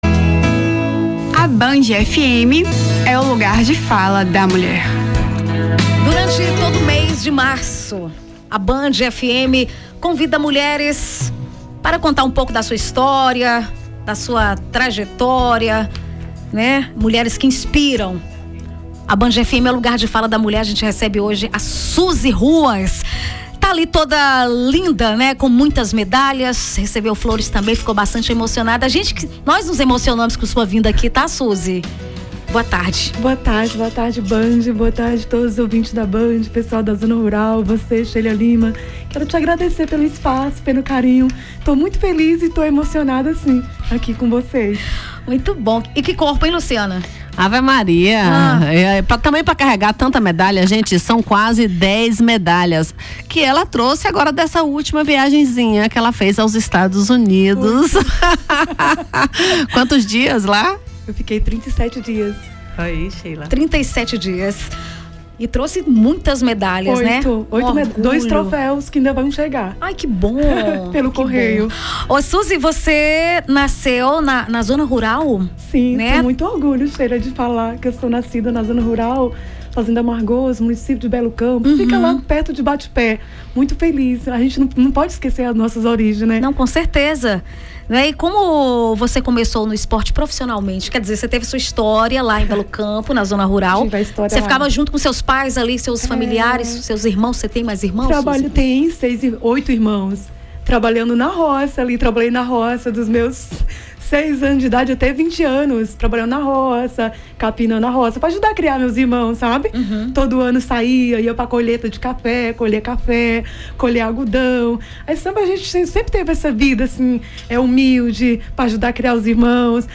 Band FM: Entrevista